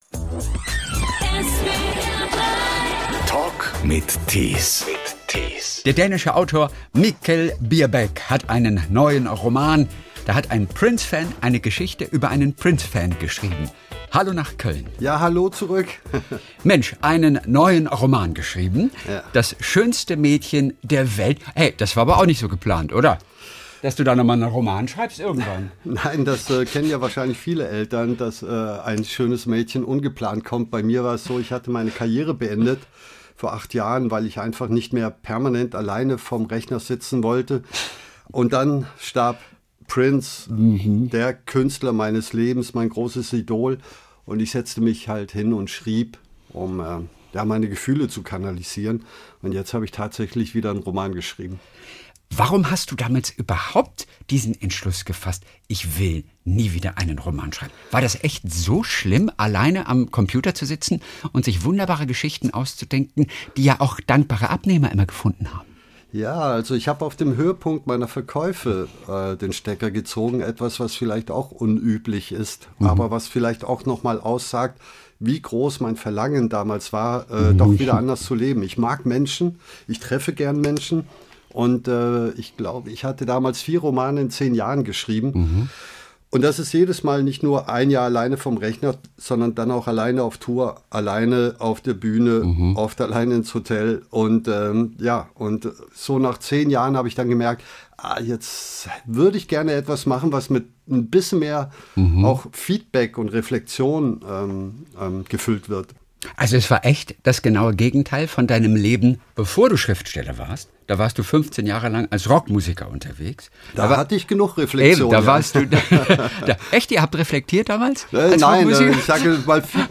Der Talk mit interessanten Menschen